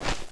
zoom_out.wav